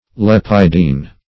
Lepidine \Lep"i*dine\ (l[e^]p"[i^]*d[i^]n or *d[=e]n), n.
lepidine.mp3